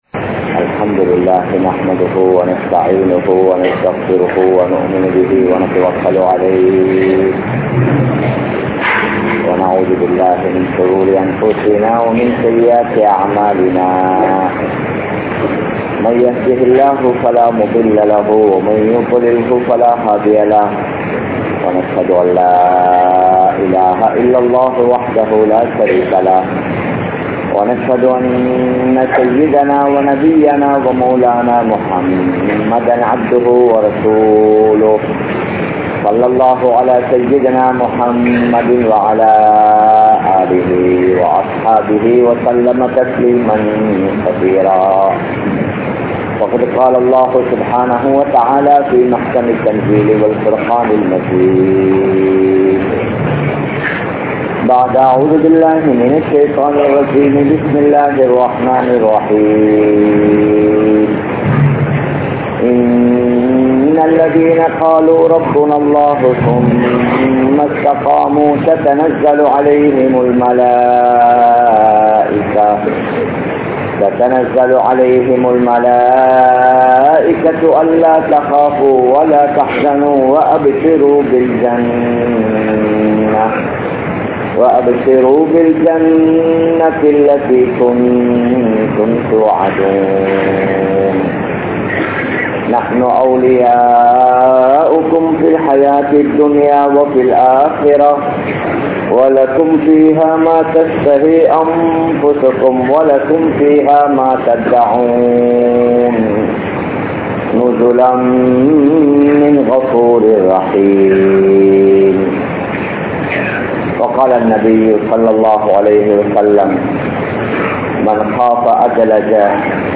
Dhauwath Seium Murai (தஃவத் செய்யும் முறை) | Audio Bayans | All Ceylon Muslim Youth Community | Addalaichenai